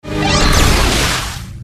SGU_2x06_Nakai-Handwaffe.mp3